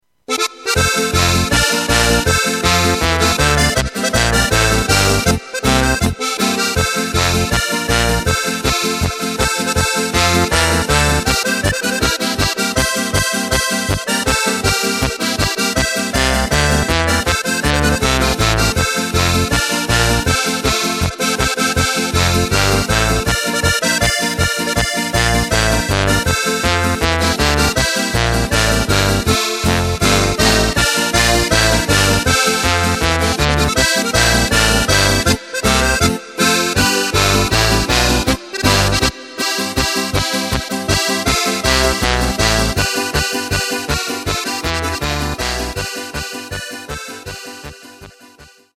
Tempo:         160.00
Tonart:            F
Flotte Polka aus dem Jahr 2020!
Playback mp3 Demo